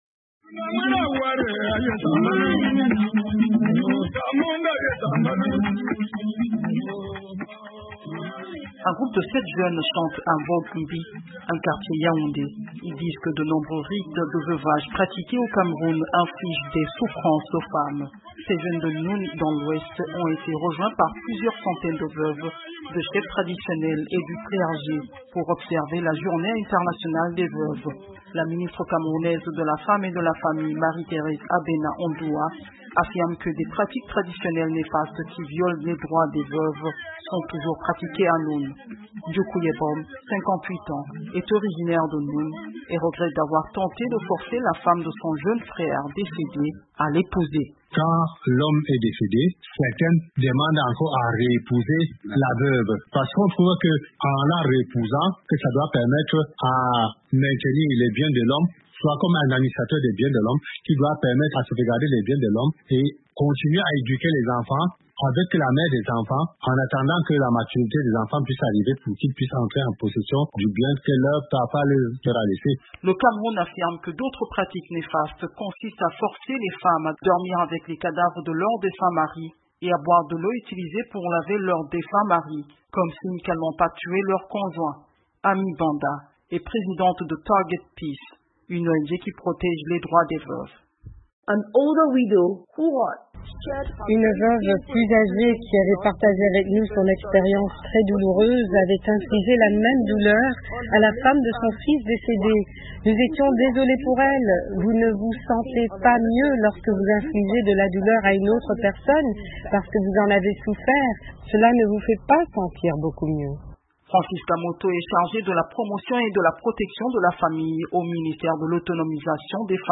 C’est un repportage